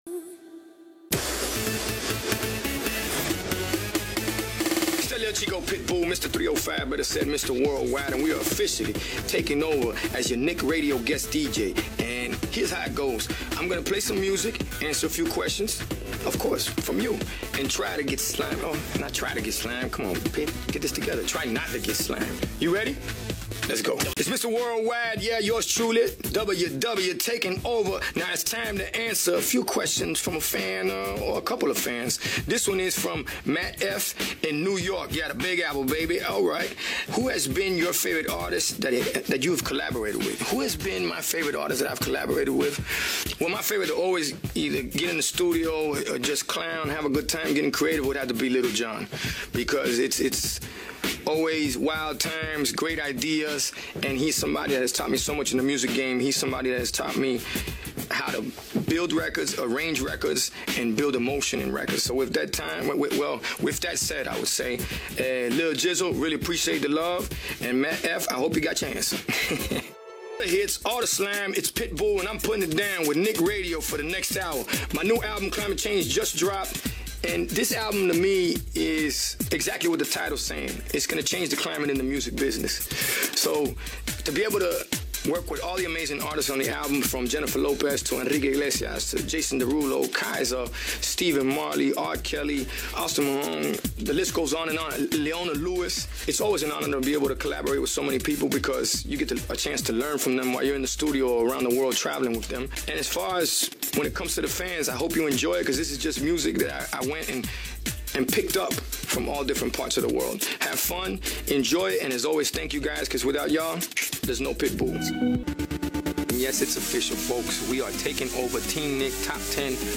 [AUDIO]: Pitbull Takes Over Nick Radio as Guest DJ
Pitbull took over Nick Radio this week to serve as Guest DJ. During his take over, he played some music, answered fan questions, told some fun facts about himself, and more.